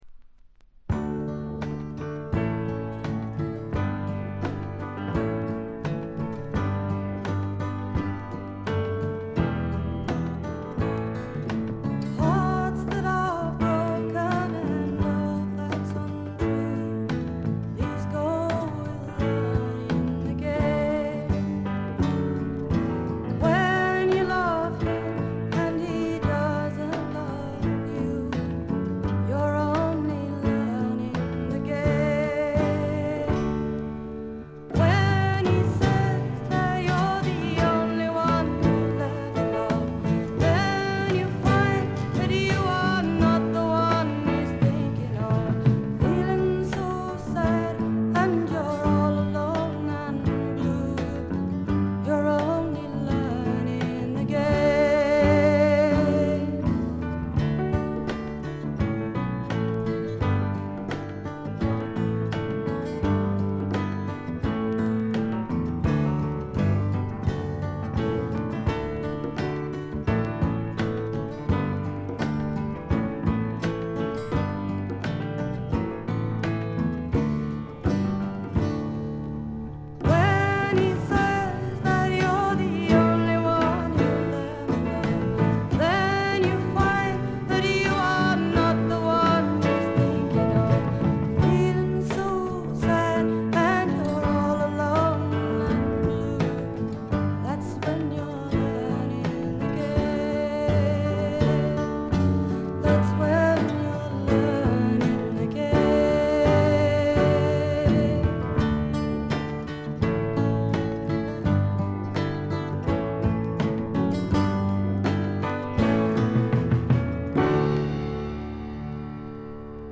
部分試聴ですが軽微なチリプチ少し。
試聴曲は現品からの取り込み音源です。